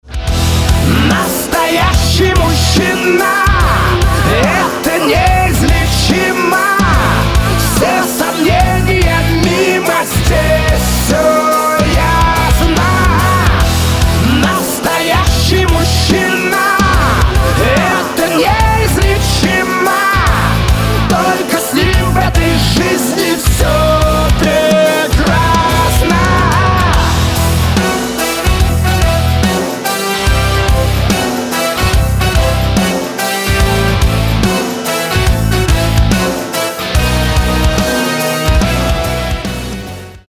Эстрадные
Поп